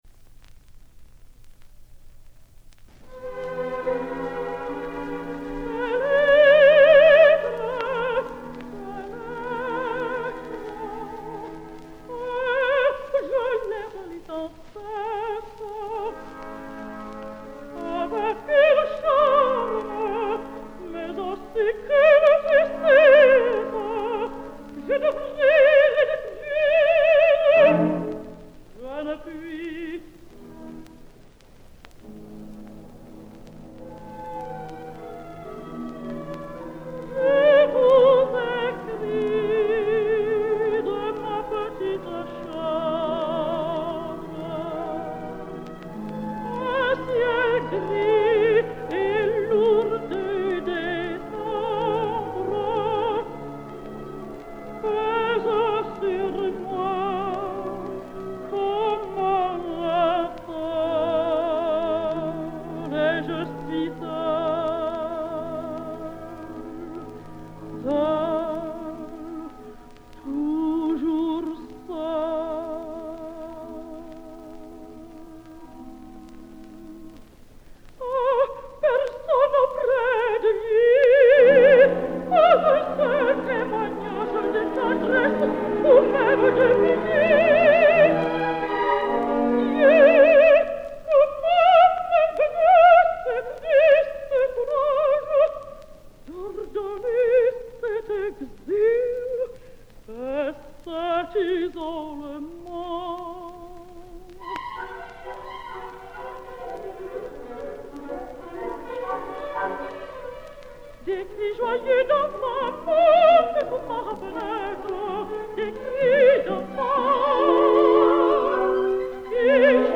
L’harmonia és inestable amb constants modulacions que il·lustren l’agitació del personatge.